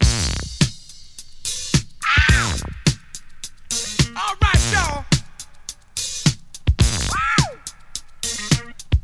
• 106 Bpm Fresh Drum Beat D# Key.wav
Free drum groove - kick tuned to the D# note. Loudest frequency: 2321Hz
106-bpm-fresh-drum-beat-d-sharp-key-EJY.wav